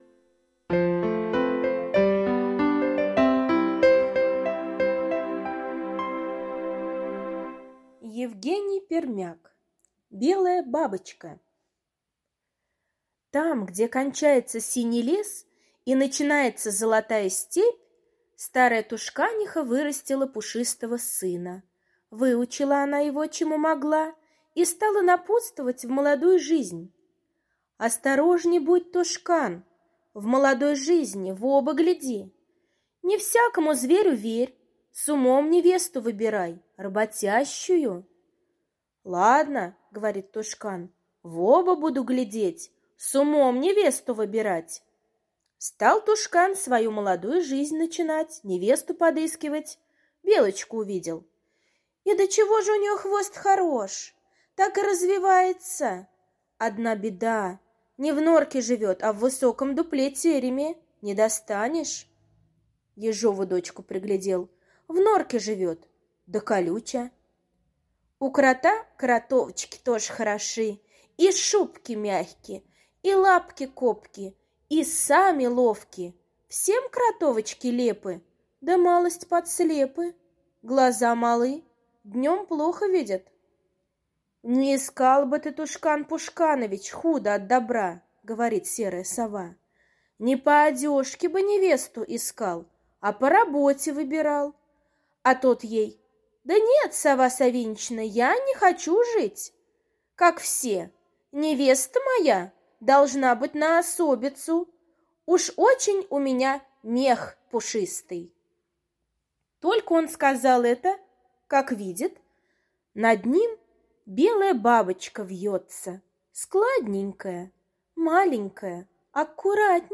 Белая бабочка — аудиосказка Пермяка Е. Сказка про молодого Тушкана, который выбирал себе невесту.